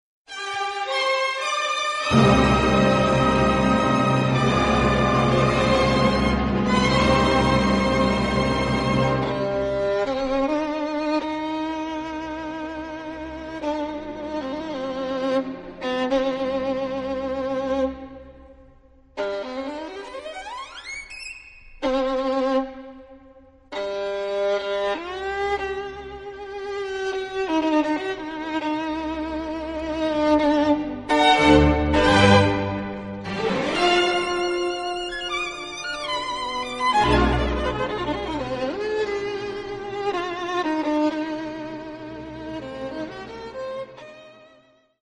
【所屬類別】 XRCD唱片　　古典音樂
for violin & piano